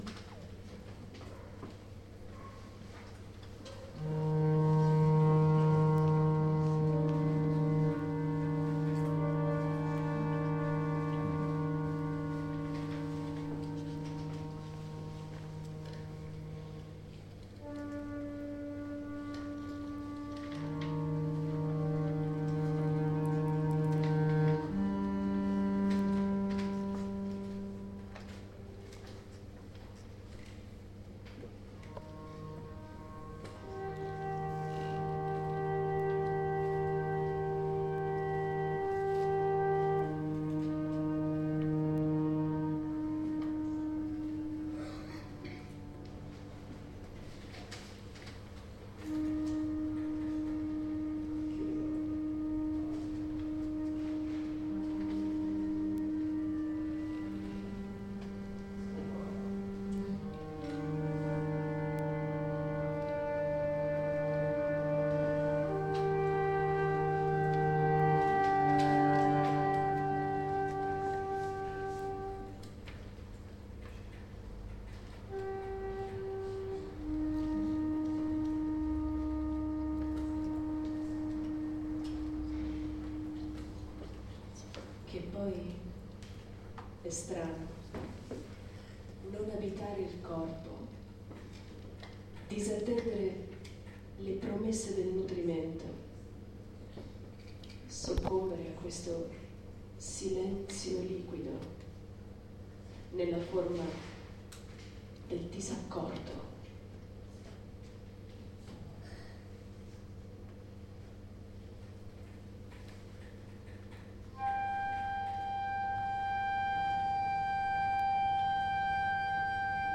File audio del Concerto, di compositori e interpreti del Conservatorio Bonporti, nell’ambito del Festival di poesia Anterem a Verona
Il concerto si è tenuto domenica 24 ottobre alla Biblioteca Civica di Verona, alle ore 11.00.
Valentina Massetti (1984), Dinamiche del disaccordo per voce recitante, flauto, sassofono contraltotesto di Giovanni Duminuco